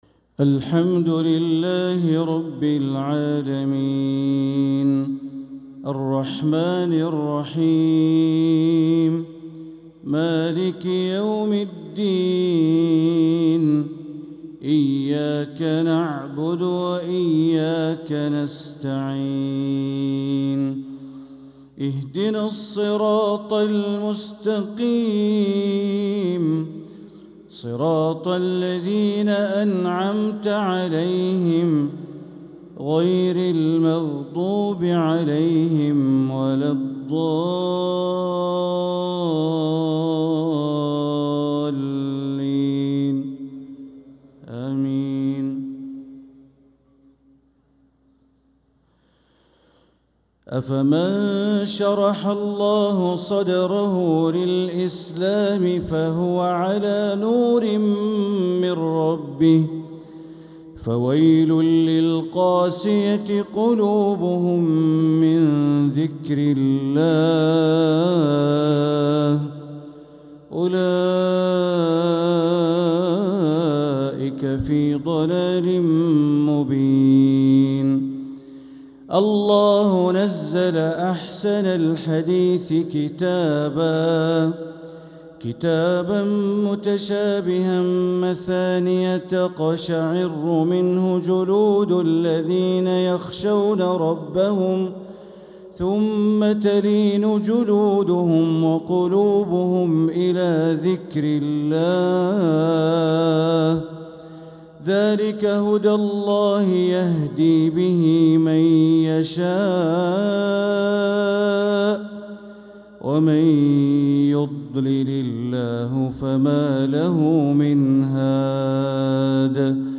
تلاوة من سورة الزمر | عشاء الأحد 3-8-1446هـ > 1446هـ > الفروض - تلاوات بندر بليلة